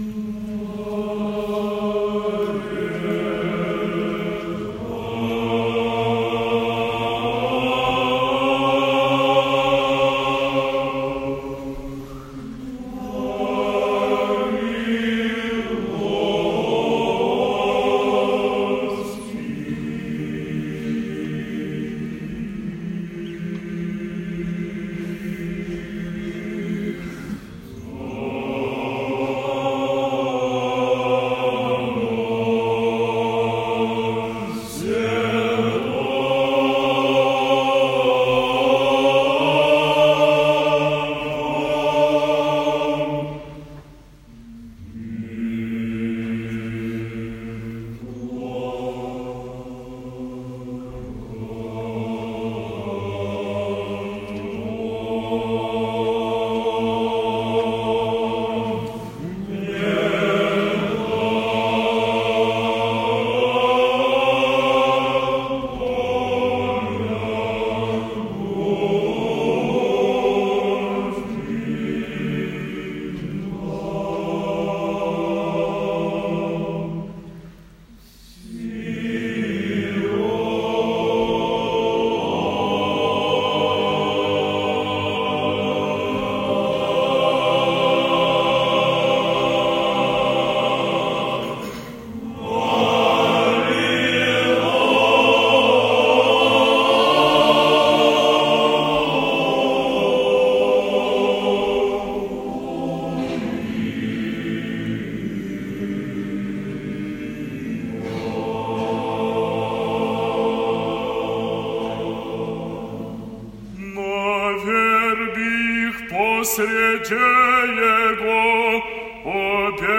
Напередодні Неділі сиропусної Предстоятель очолив всенічне бдіння у Києво-Печерській Лаврі (+аудіо) - Українська Православна Церква
17 лютого 2018 року, напередодні Неділі сиропусної, Предстоятель Української Православної Церви Блаженніший Митрополит Київський і всієї України Онуфрій звершив всенічне бдіння у Трапезному храмі преподобних Антонія і Феодосія Свято-Успенської Києво-Печерської Лаври.
За богослужінням молились студенти Київських духовних шкіл, численні паломники та парафіяни обителі.